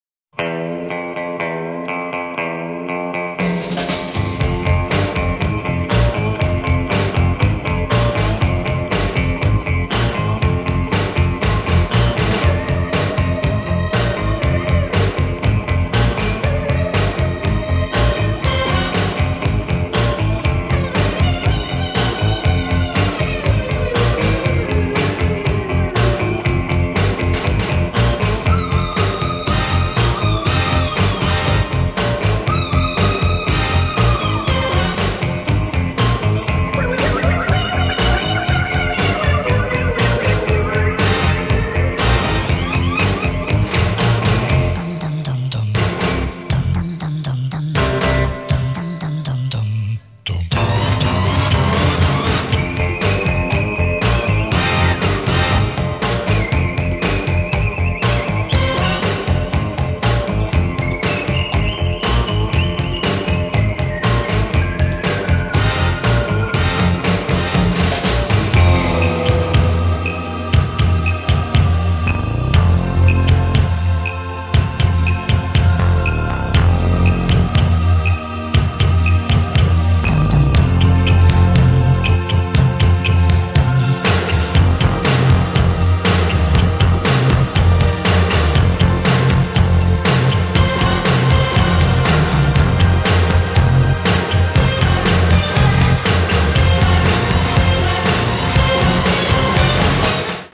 • Качество: 128, Stereo
гитара
свист
брутальные
электрогитара